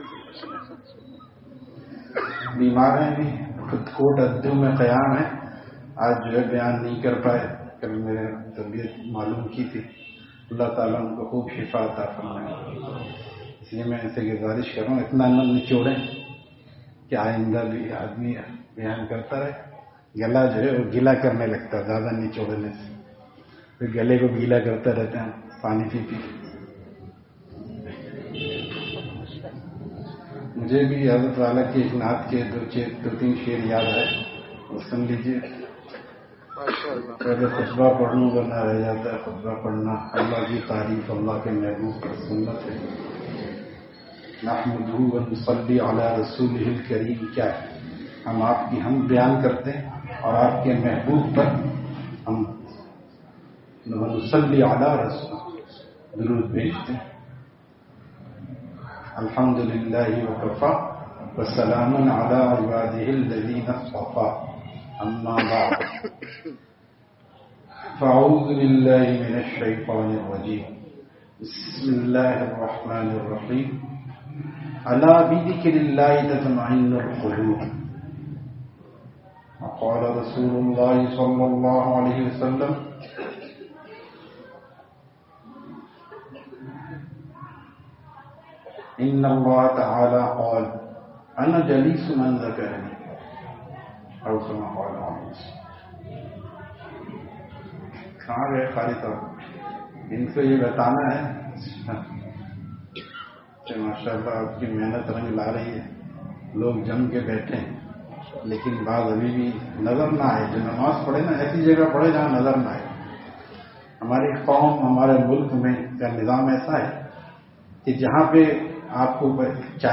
Bayan After Isha at Qasim Masjid, Choti Ghatki, Hyderabad